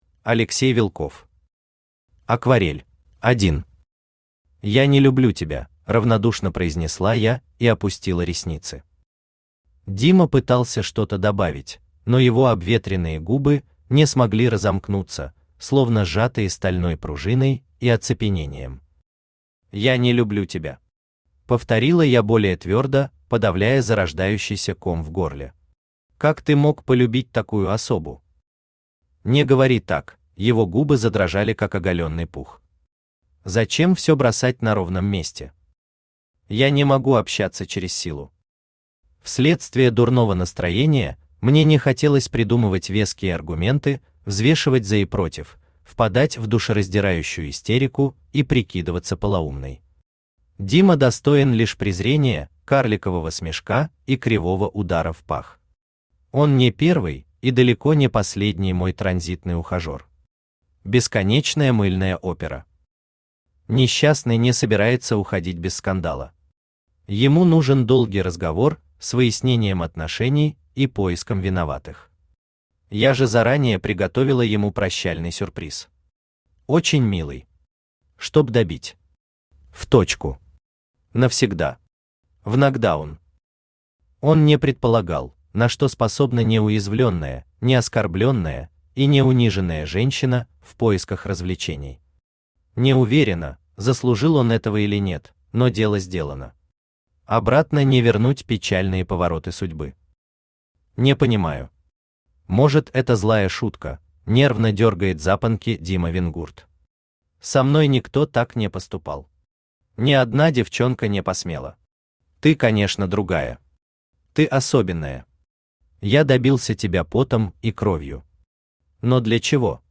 Аудиокнига Акварель | Библиотека аудиокниг
Aудиокнига Акварель Автор Алексей Сергеевич Вилков Читает аудиокнигу Авточтец ЛитРес.